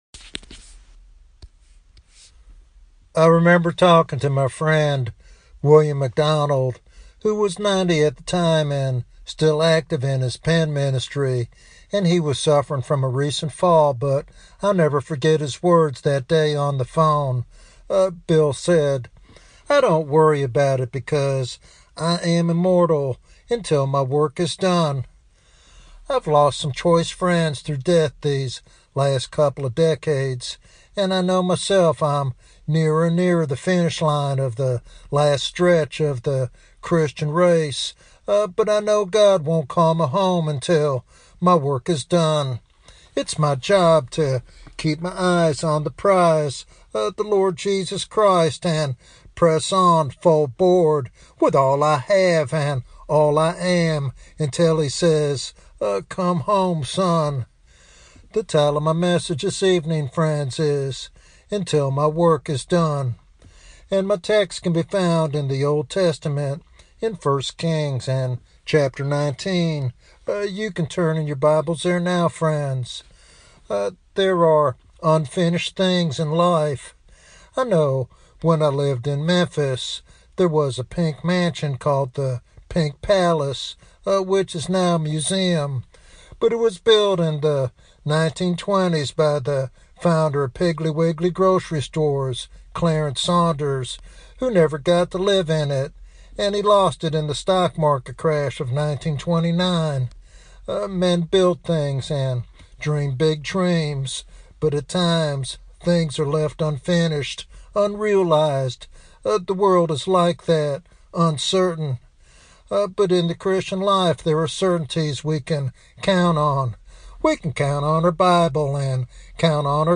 This devotional message inspires Christians to trust God's timing and remain steadfast in their calling.